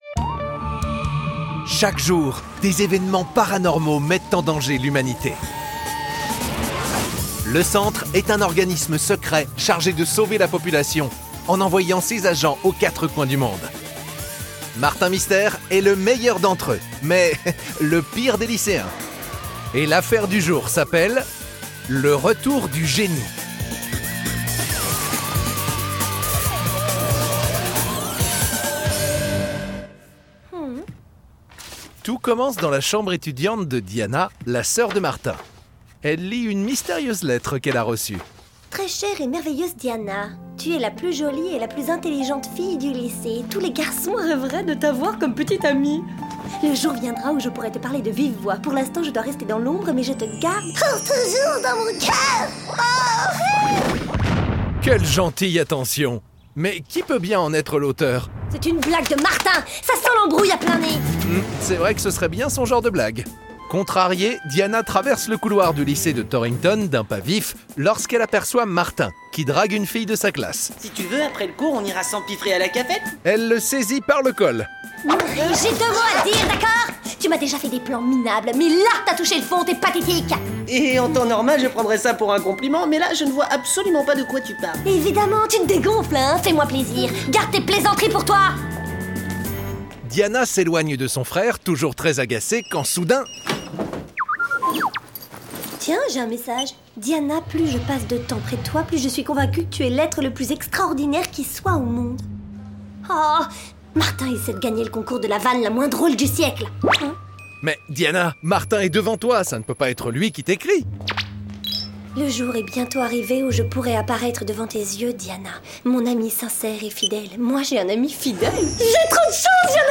Plongez dans l'univers mystérieux de Martin Mystère avec 6 histoires audio paranormales.